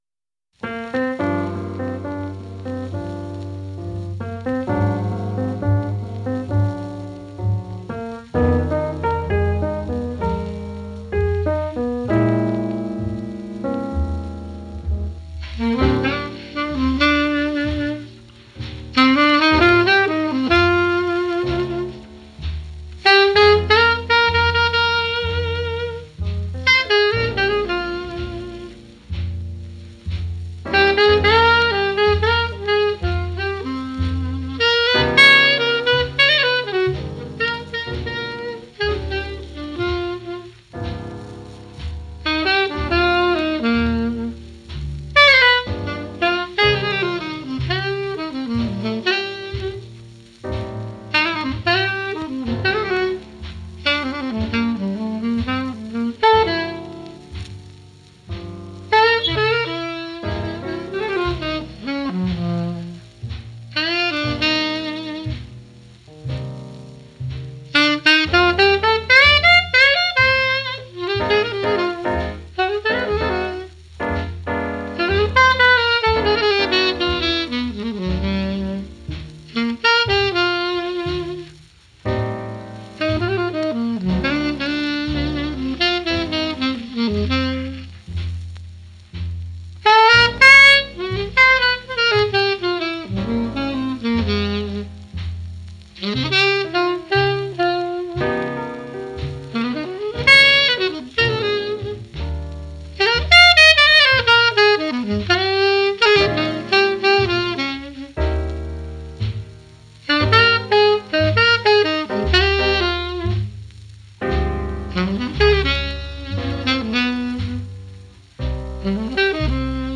alto sax